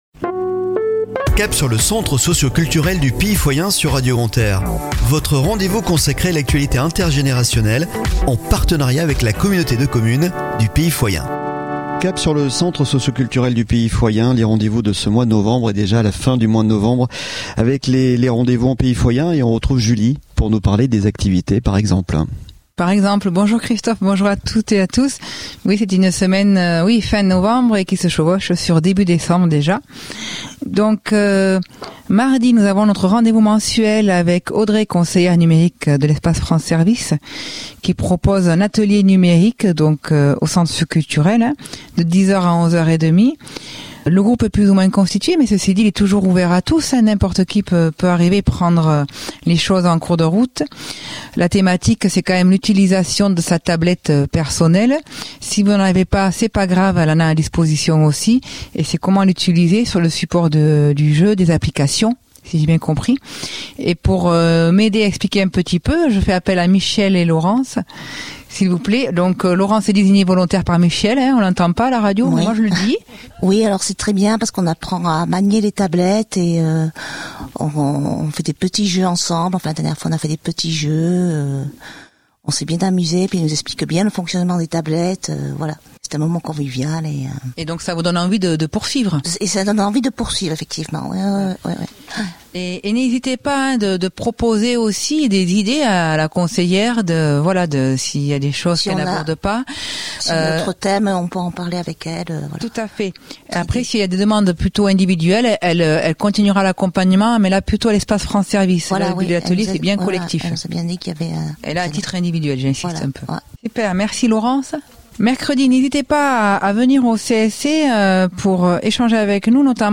Chronique de la semaine du 28 Novembre au 04 Décembre 2022 !